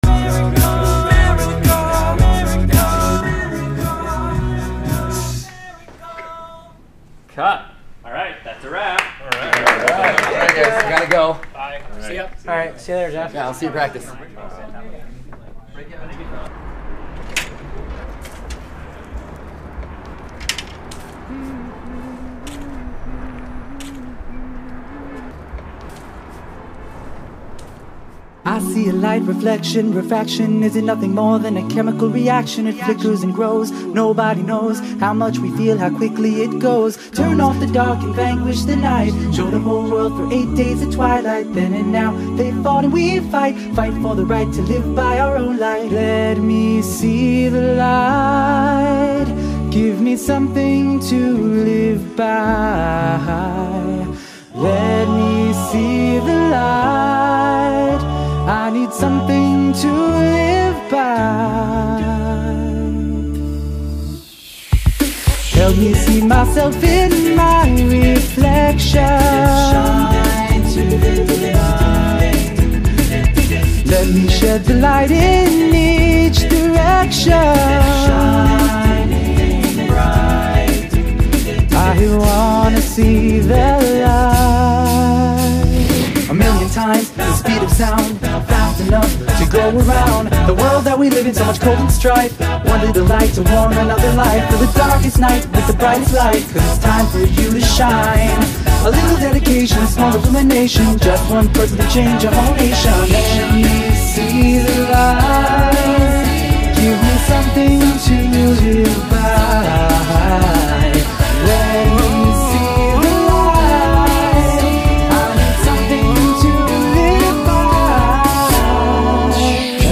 שירי אקפלה
כל הקולות מיוצרים ע" קולות מהפה.
זה עבודת צוות מטורפת הם בערך 10 גברים .